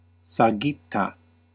Ääntäminen
IPA: /piːl/